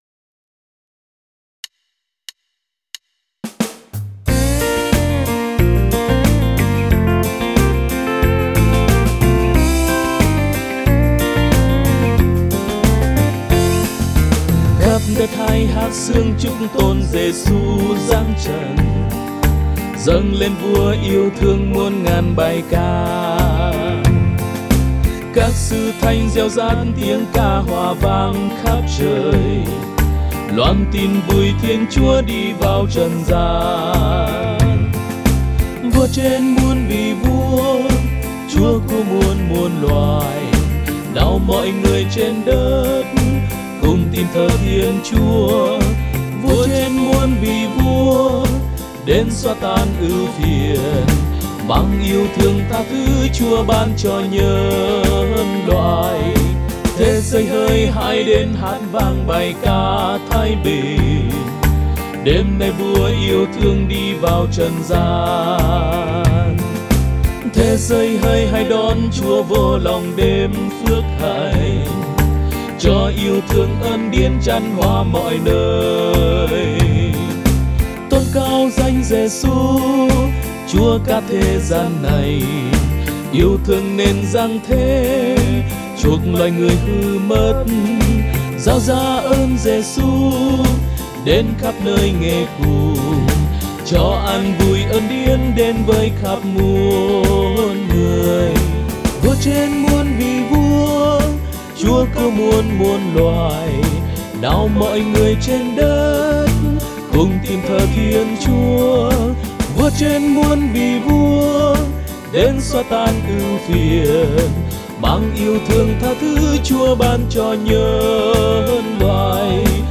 Audio Nhạc Nhạc Thánh Sáng Tác Mới Bài hát Giáng sinh